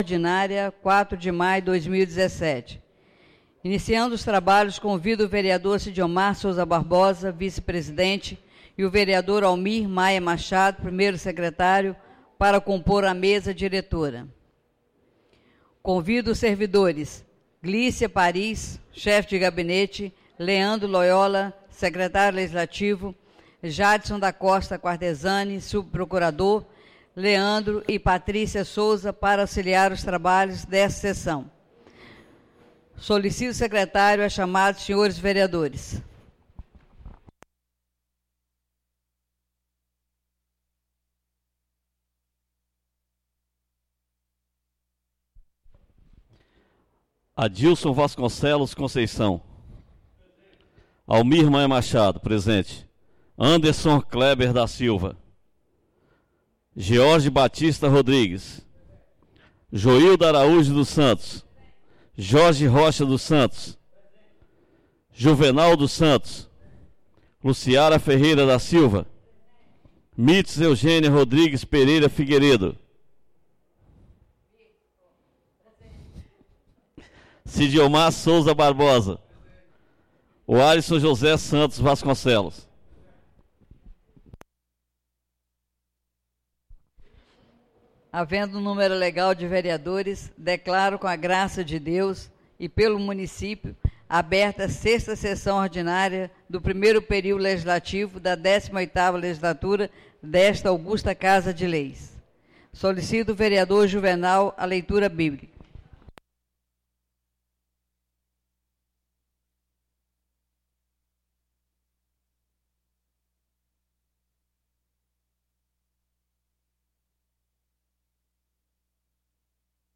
6ª (SEXTA) SESSÃO ORDINÁRIA DO DIA 04 DE MAIO DE 2017 SEDE